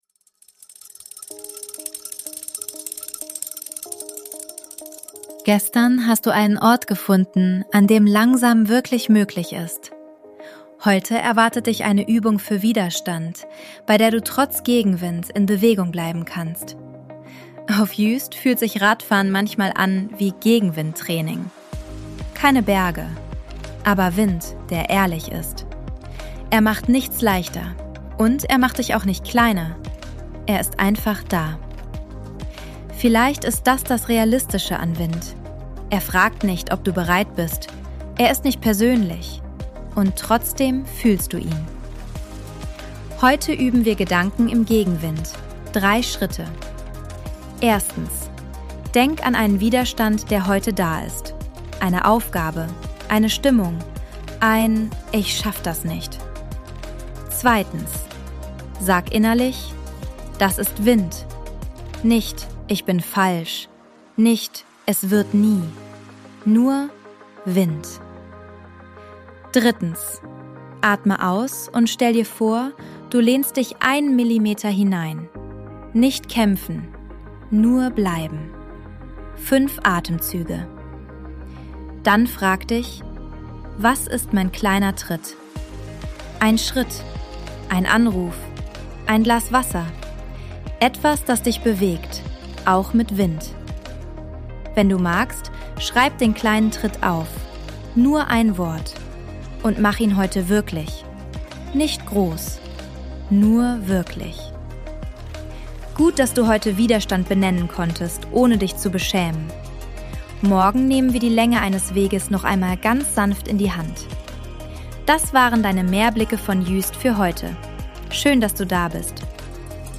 Impulse zur Achtsamkeit von der Nordseeinsel Juist
Sounds & Mix: ElevenLabs und eigene Atmos